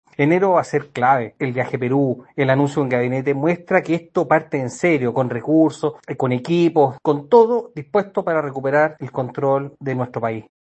En ese sentido, el diputado republicano, Agustín Romero, dijo que enero será determinante para entregar muestras de seriedad al país.